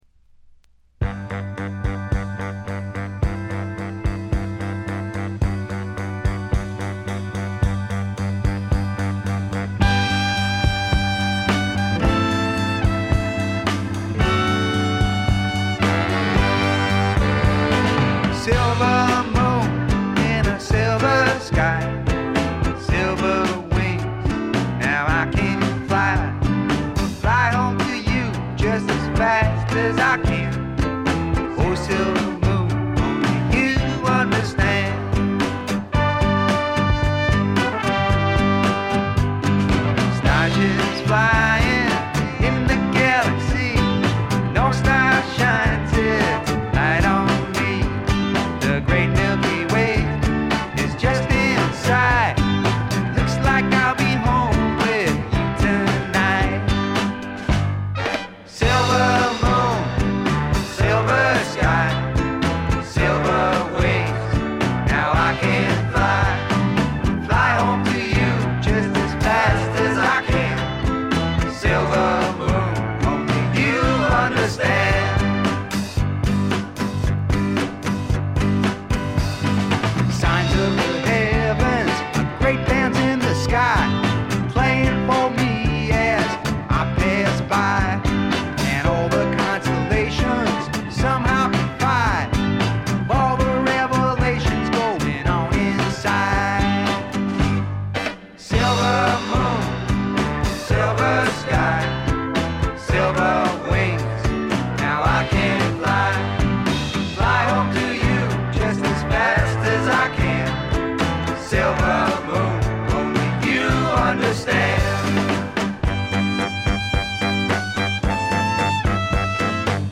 ほとんどノイズ感無し。
スワンプ系シンガーソングライター基本。
試聴曲は現品からの取り込み音源です。
Recorded at Muscle Shoals Sound Studios, Muscle Shoals, Ala.